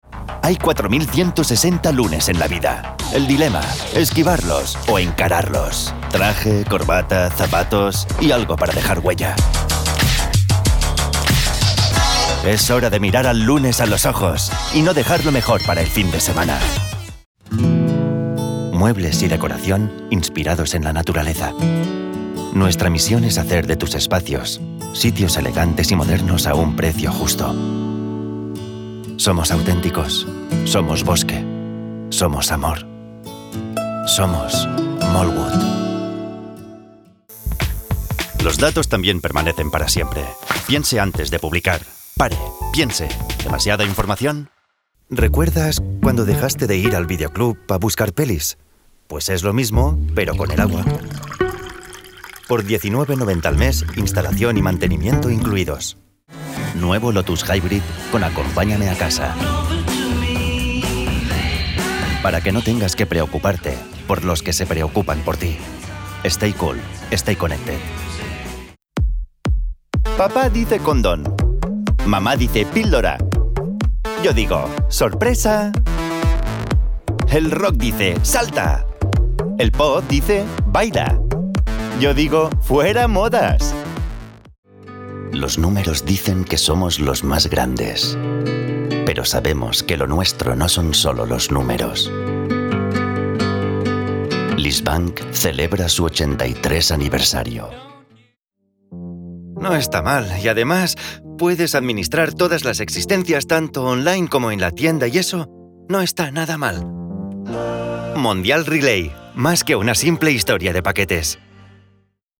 Commercial